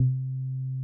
Bass WUSYANAME.wav